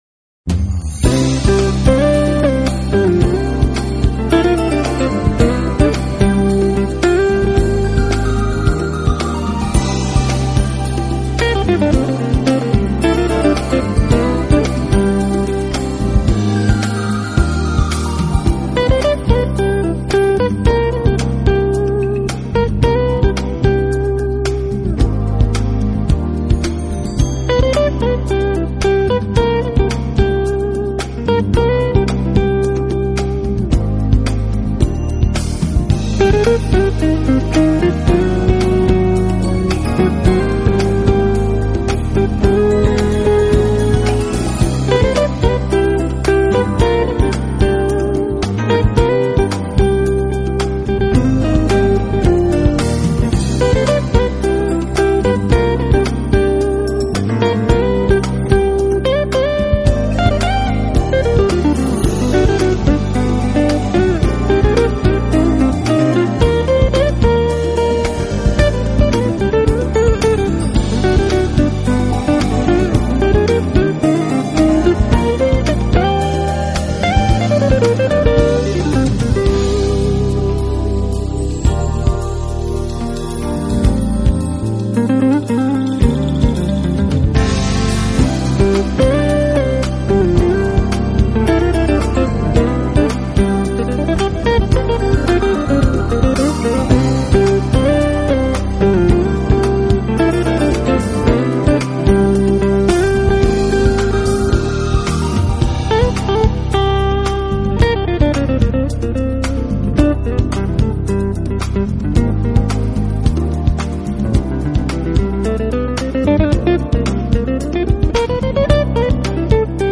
音乐类型：Pop
音乐风格：Smooth Jazz, Soul Jazz